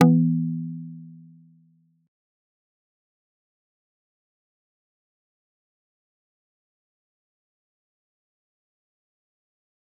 G_Kalimba-E3-pp.wav